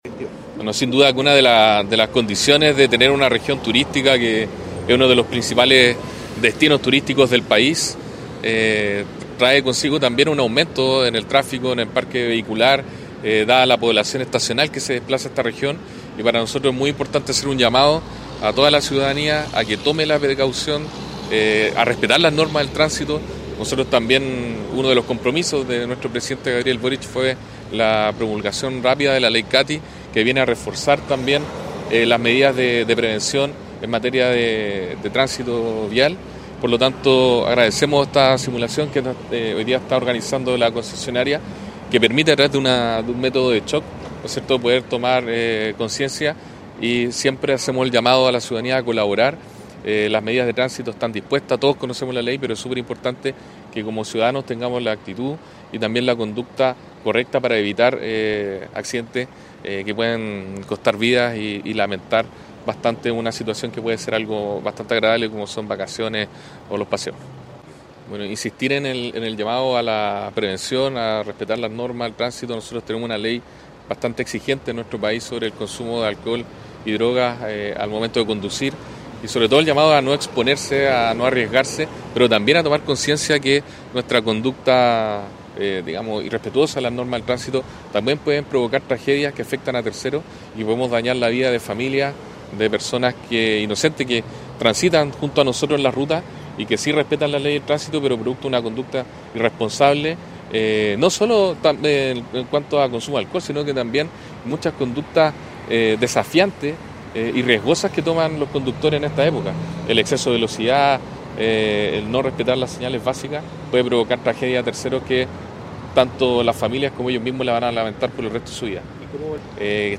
CAMPANA-TRANSITO-Delegado-Presidencial-Galo-Luna.mp3